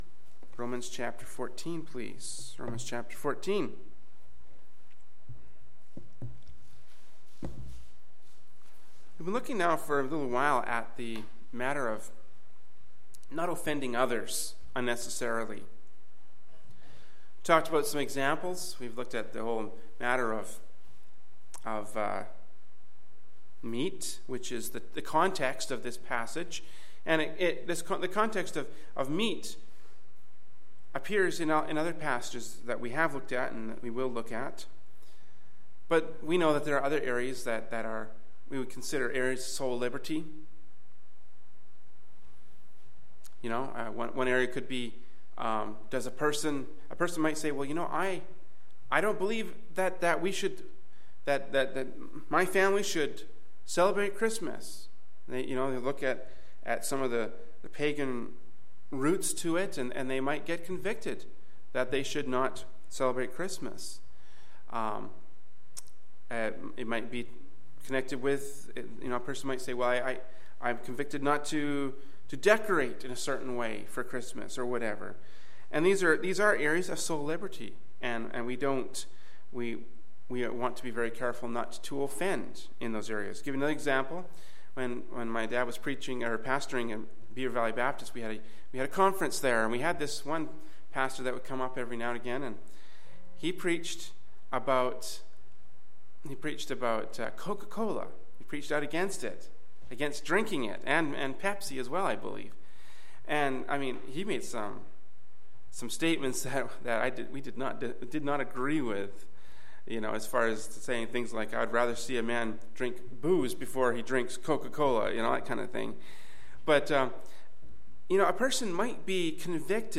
Kamloops, B.C. Canada
Adult Sunday School